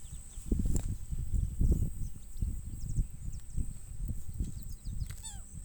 Graveteiro (Phacellodomus ruber)
Se escucha muy despacio
Detalhada localização: Dique Río Hondo
Condição: Selvagem
Certeza: Gravado Vocal
Espinerogrande.mp3